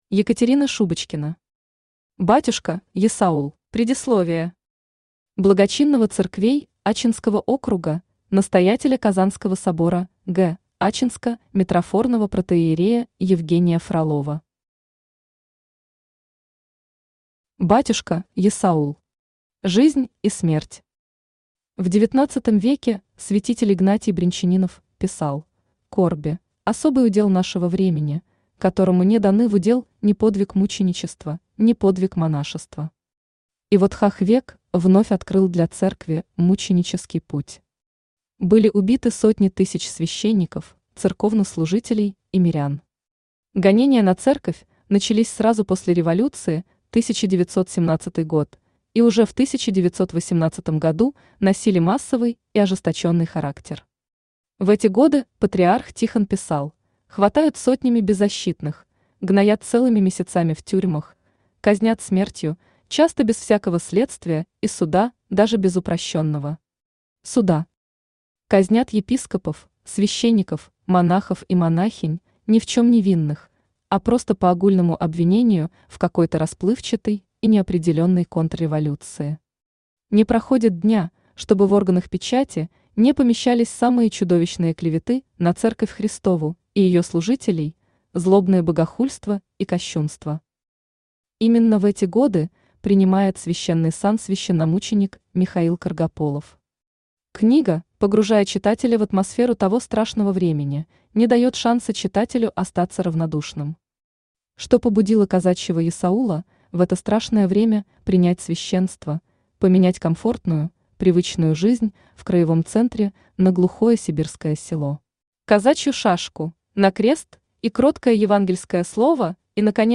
Аудиокнига Батюшка есаул | Библиотека аудиокниг
Aудиокнига Батюшка есаул Автор Екатерина Шубочкина Читает аудиокнигу Авточтец ЛитРес.